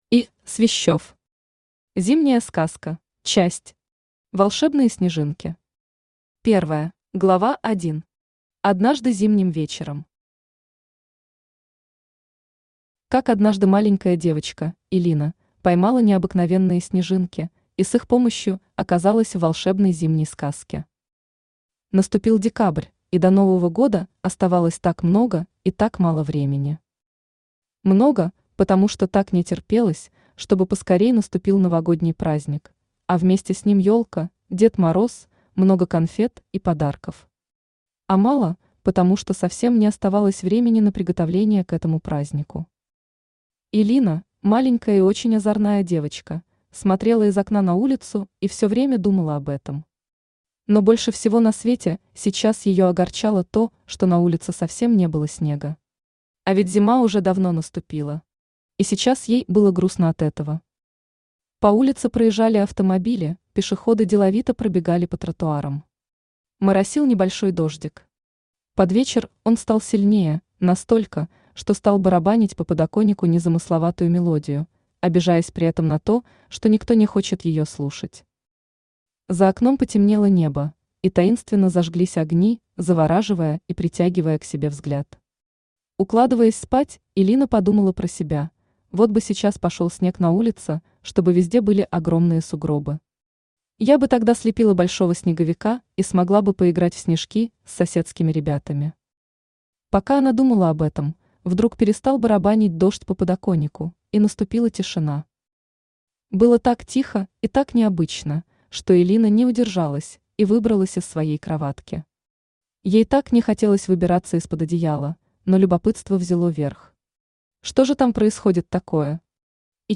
Aудиокнига Зимняя сказка Автор И. Свищёв Читает аудиокнигу Авточтец ЛитРес.